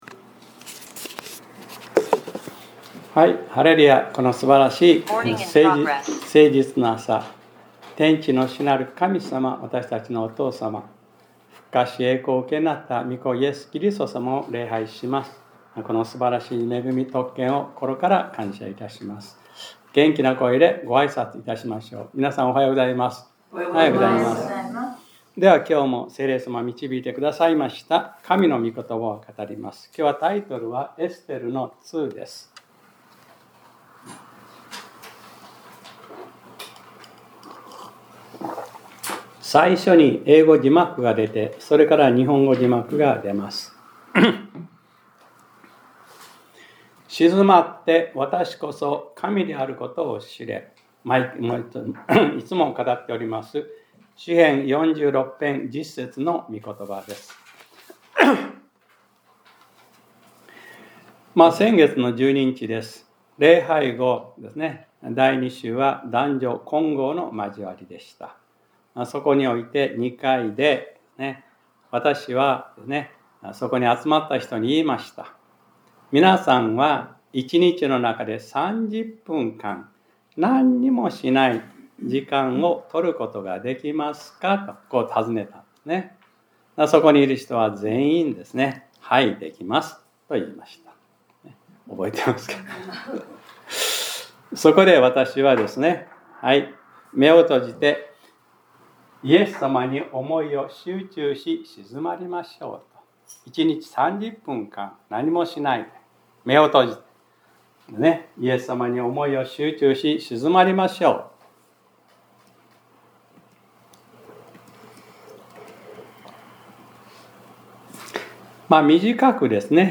2025年11月02日（日）礼拝説教『 エステル-２ 』